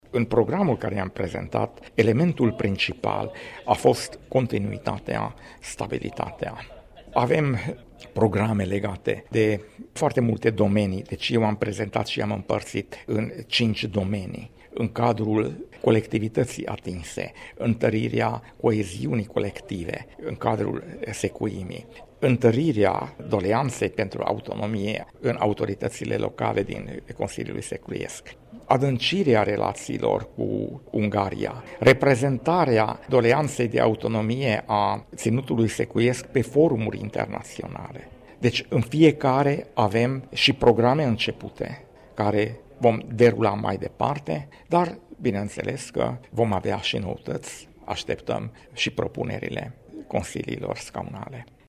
Președintele reales al Consiliului Național Secuiesc, Izsák Balázs, a declarat ieri, în cadrul Congresului CNS de la Tîrgu Mureș, că organizația are un statut de autonomie a ținutului secuiesc și că dorește să obțină autonomia pe cale constituțională, nu prin compromisuri politice: